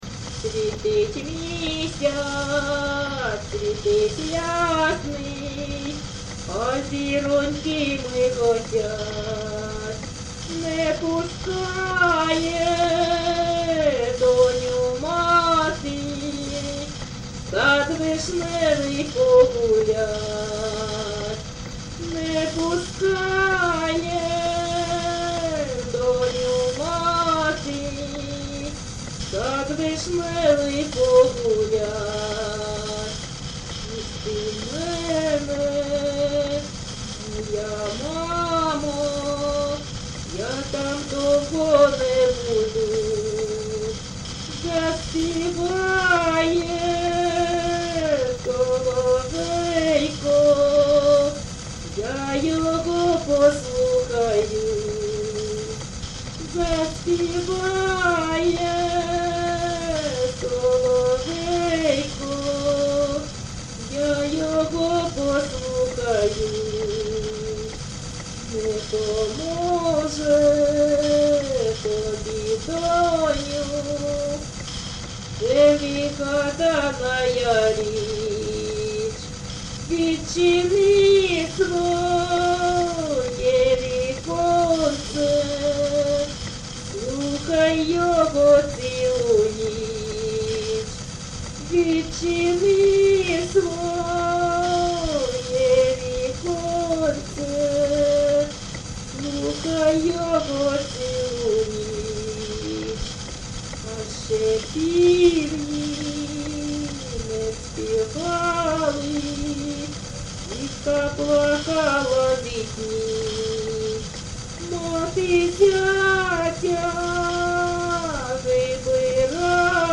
ЖанрПісні з особистого та родинного життя
Місце записус. Рідкодуб, Краснолиманський (Лиманський) район, Донецька обл., Україна, Слобожанщина